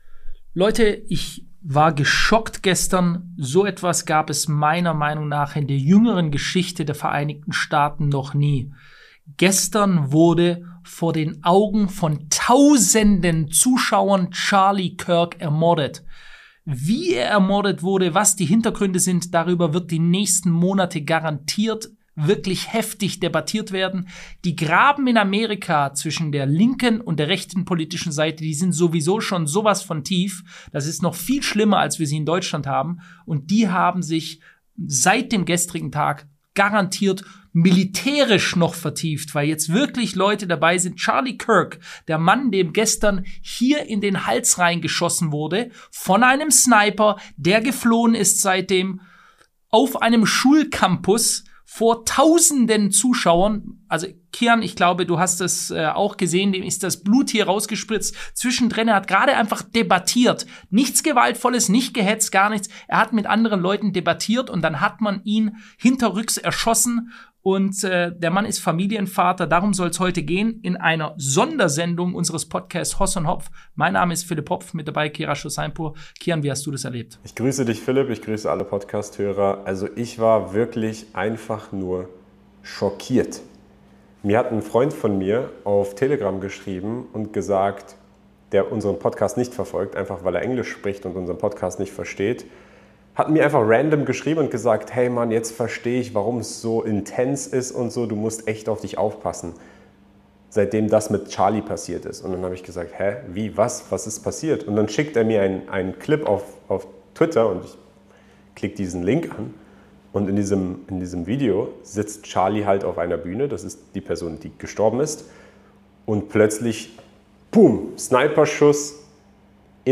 Eine facettenreiche Diskussion über Redefreiheit, gesellschaftliche Spaltung, politischen Extremismus und die Fragilität des demokratischen Dialogs.